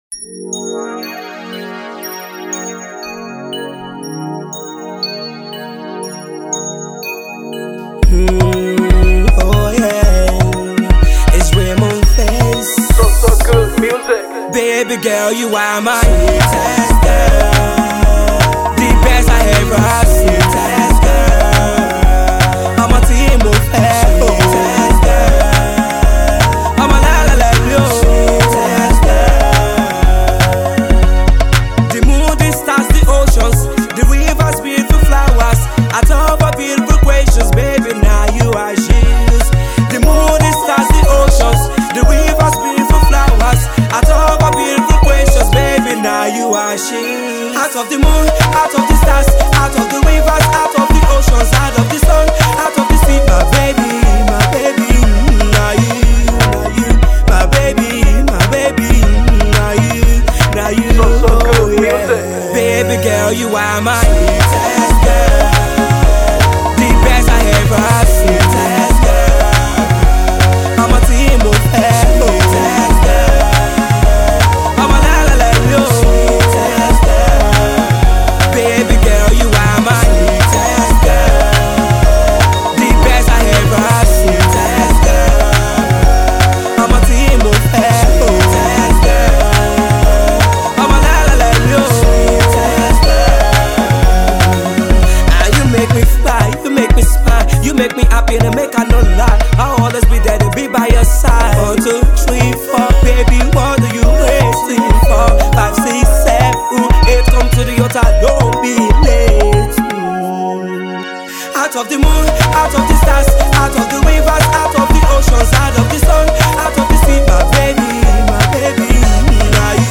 emotional yet catchy uptempo tune